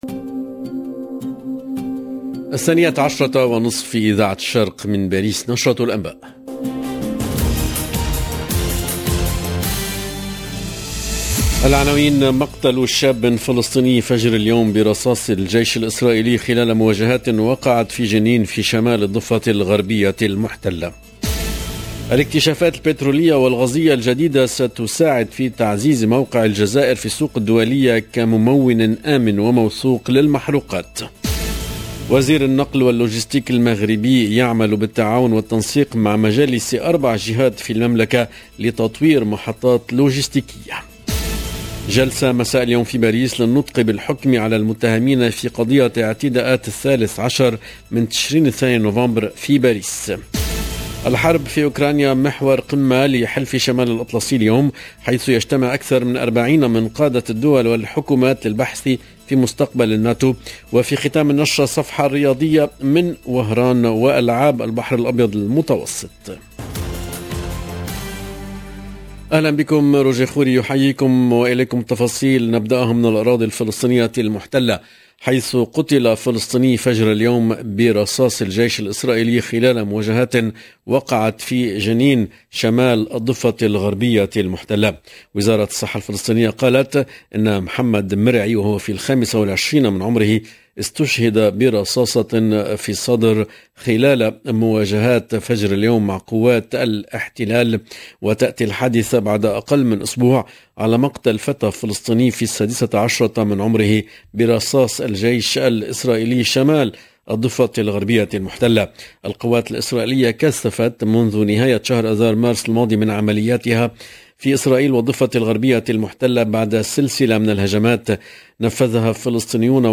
LE JOURNAL DE MIDI 30 EN LANGUE ARABE DU 29/06/22